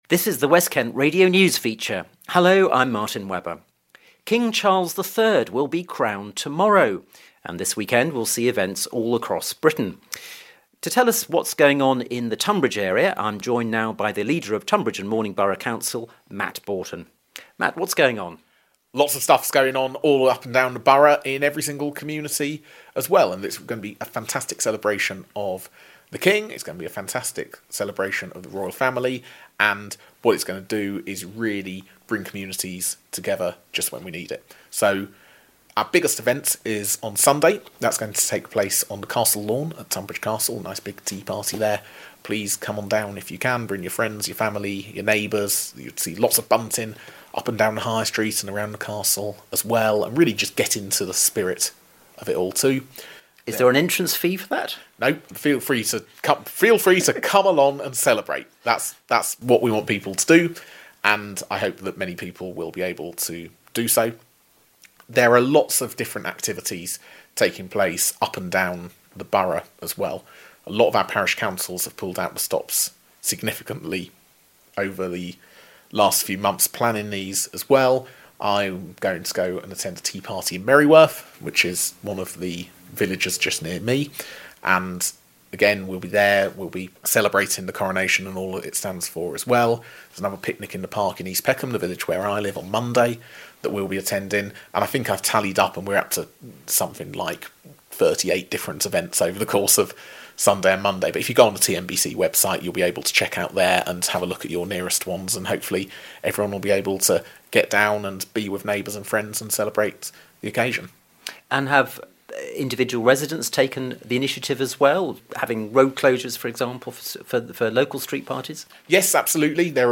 has been speaking to the organisers of events in Tunbridge Wells and Tonbridge which are taking place this weekend to help celebrate the King's Coronation.
leader of Tonbridge & Malling Borough Council about events taking place at Tonbridge Castle.